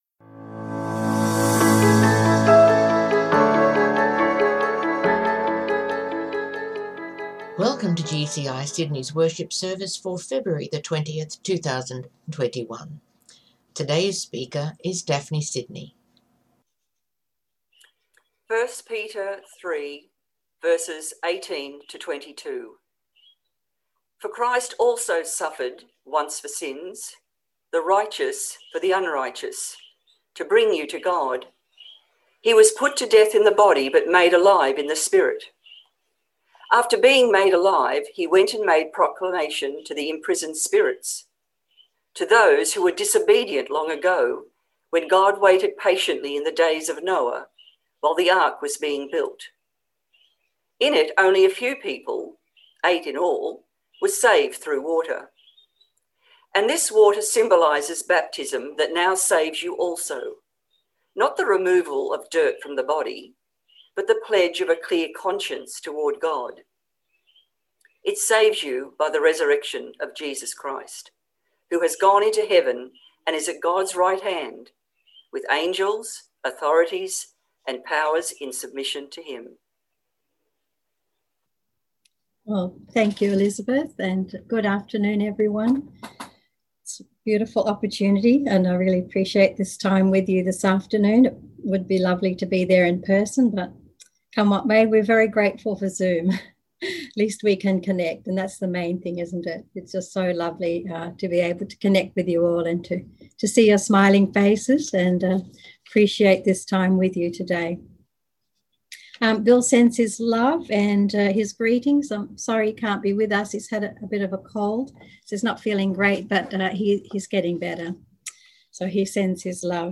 From Series: "Services"